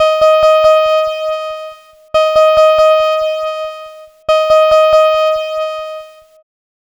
Cheese Lix Synth 140-D#.wav